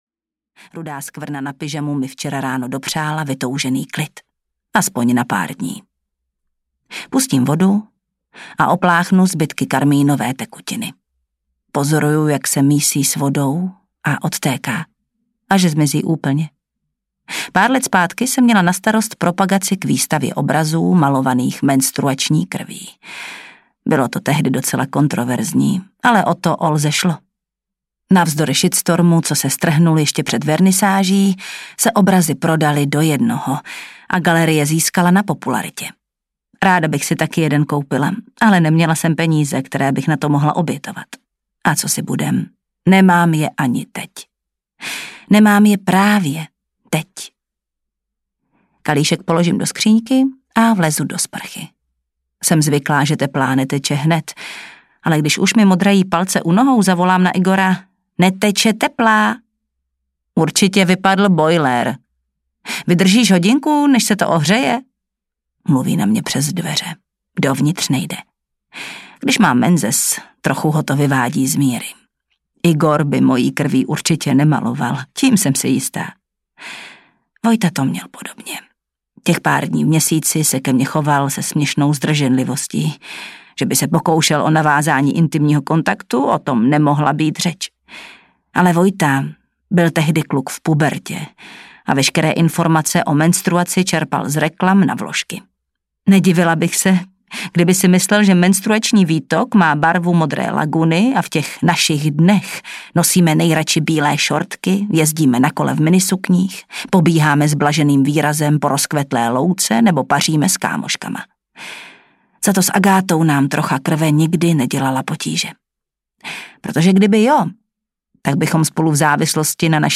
Blízcí audiokniha
Ukázka z knihy